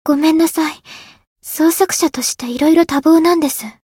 灵魂潮汐-爱莉莎-问候-不开心.ogg